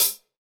PCL HH.wav